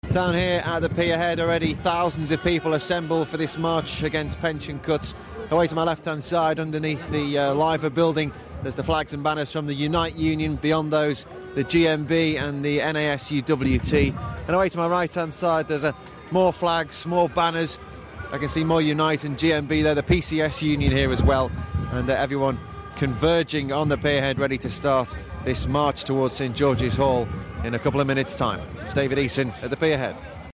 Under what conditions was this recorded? Latest report from Pier Head